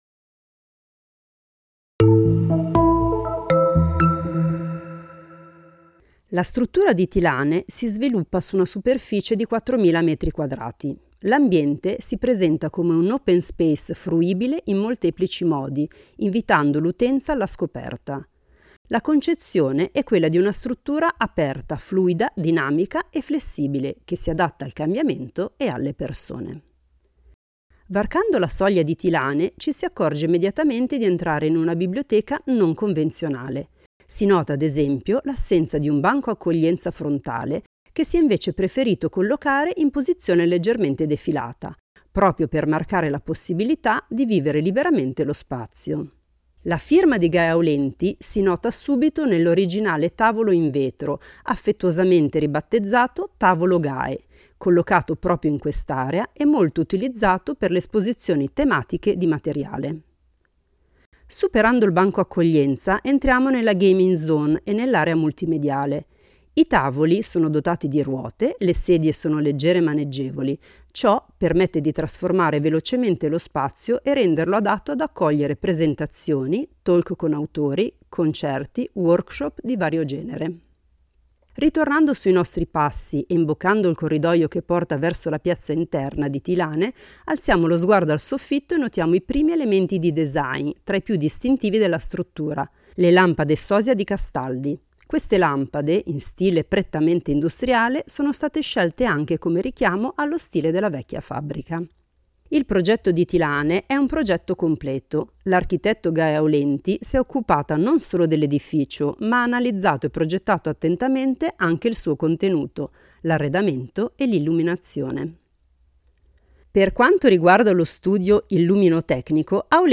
Visita guidata ad alta voce